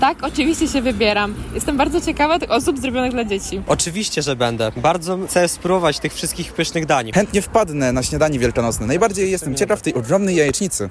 Zapytaliśmy mieszkańców Opola, czy wezmą udział w wydarzeniu: